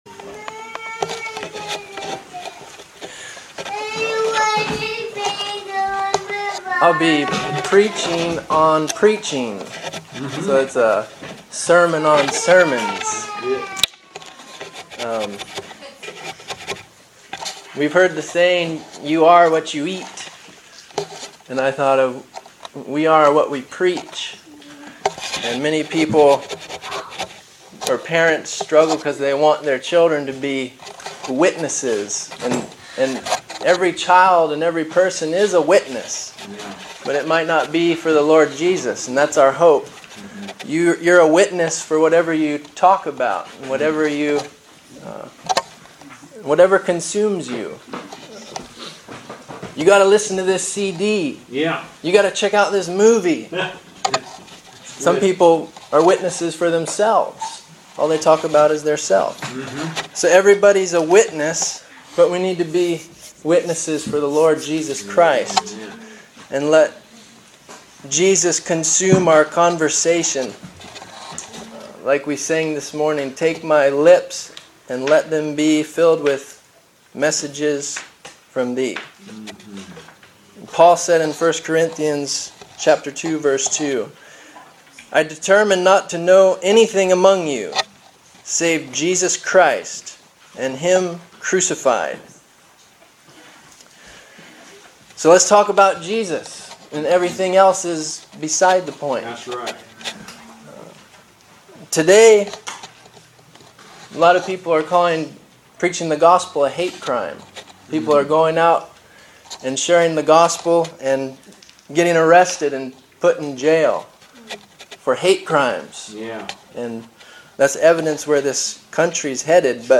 Preaching on Preaching: A sermon on sermons.